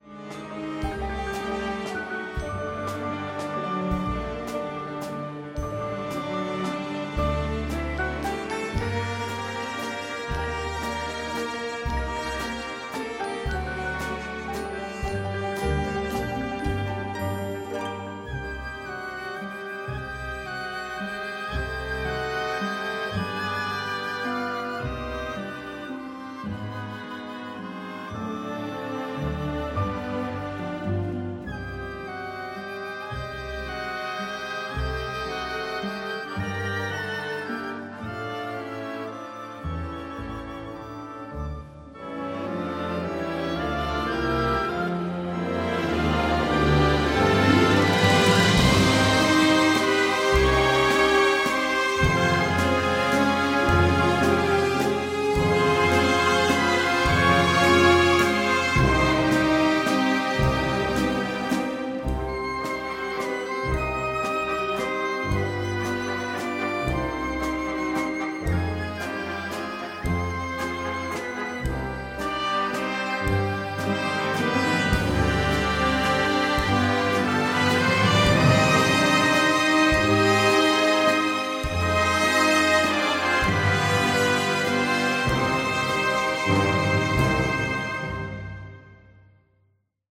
the score is operatic in its power